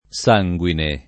sanguine
sanguine [ S#jgU ine ]